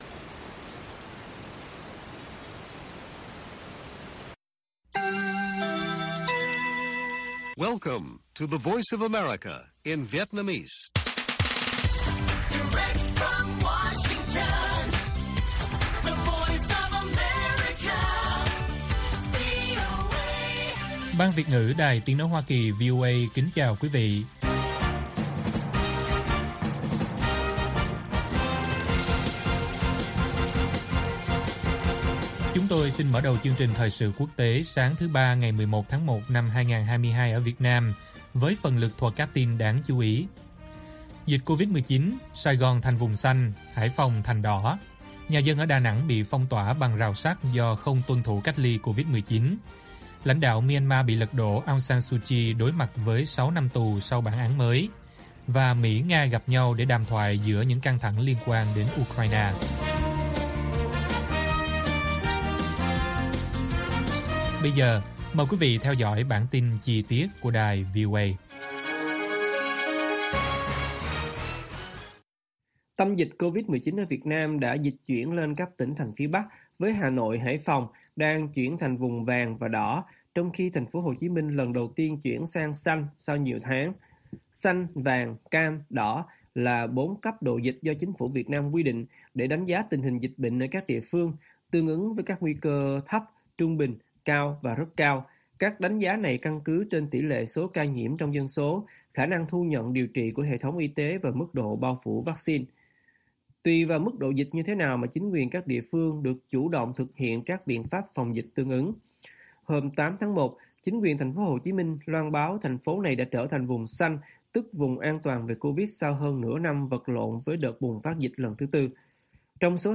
Bản tin VOA ngày 11/1/2022